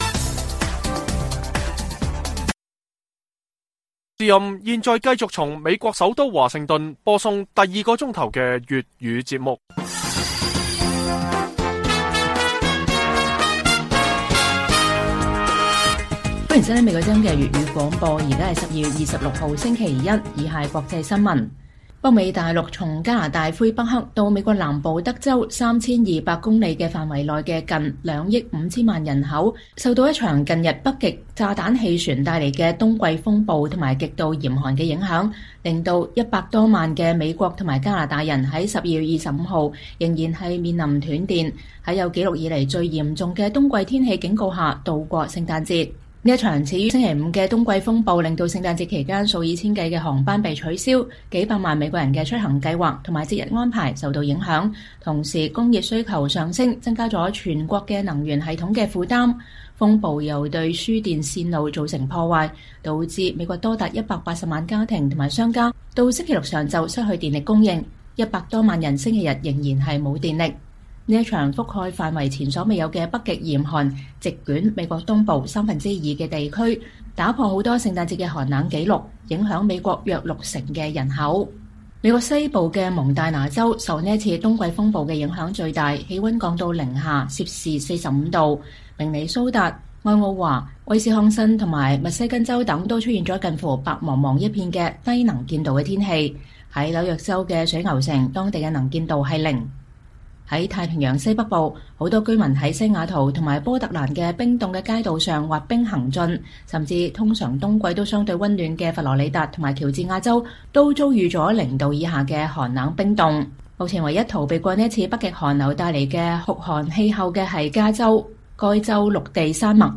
粵語新聞 晚上10-11點：創世紀極低溫寒流橫掃北美 航班大亂斷電數億美加人嚴寒中渡過聖誕節